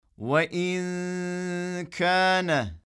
Tenvin veya Cezimli Nun’dan sonra, İhfa harflerinden biri gelirse iki harf olduğu gibi peş peşe, fakat bu sefer ğunneli olarak okunur.
Türkçede “On Tane” için “OnnTane” okunması gibidir.